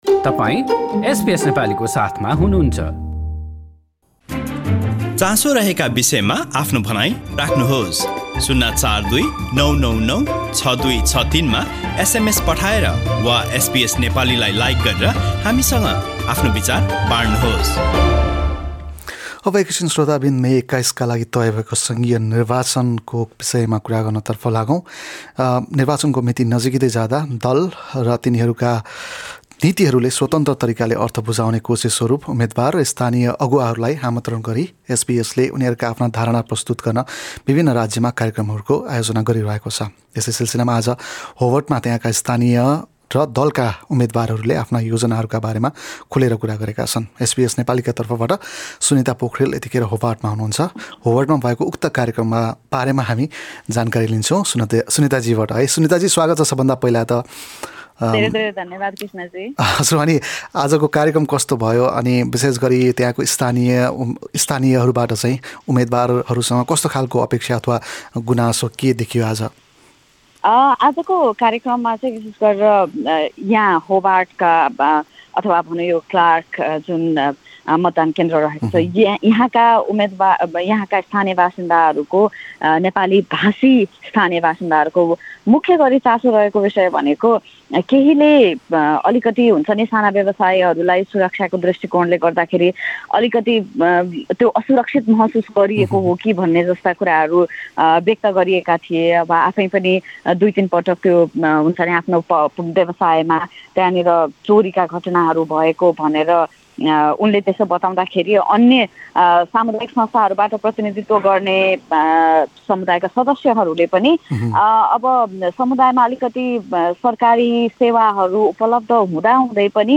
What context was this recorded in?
SBS Nepali at the 2022 Election Exchange program in Hobart, Tasmania.